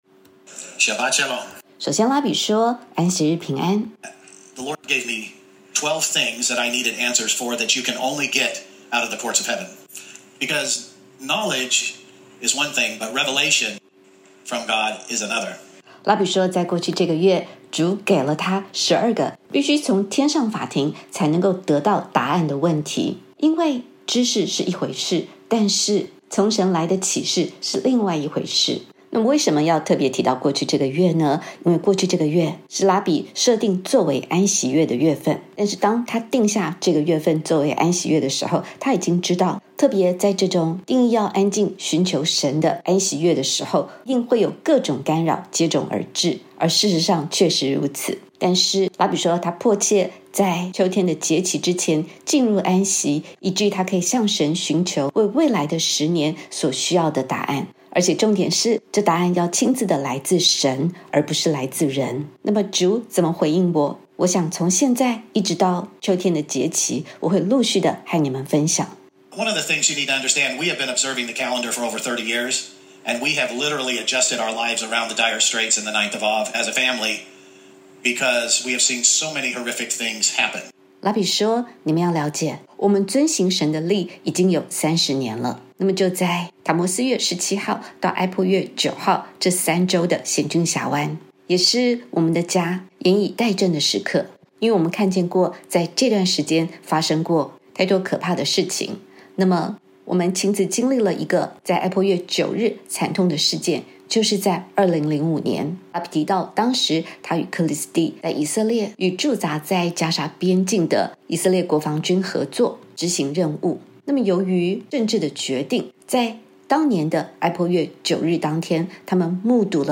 本篇感谢大卫之家 允诺口译并授权微牧刊登
首次在大卫之家安息日聚会中释出的第一个启示。